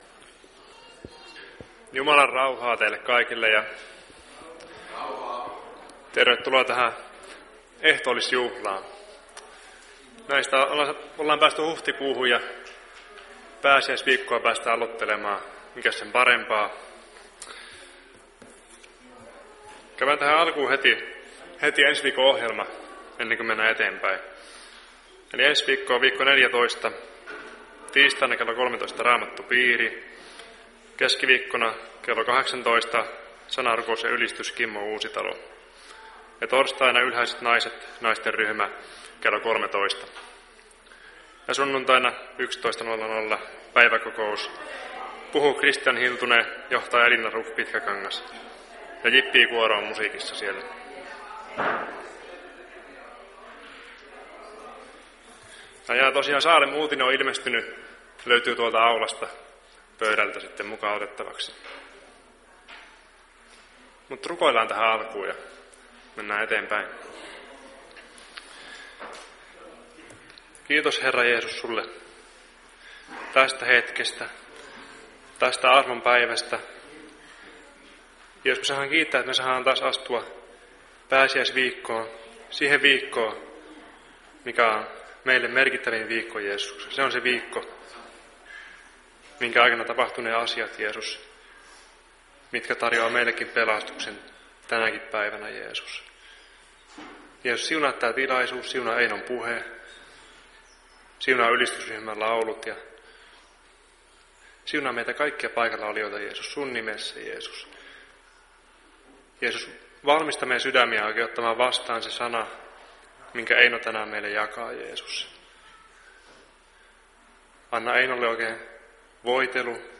Ehtoolliskokous 2.4.2023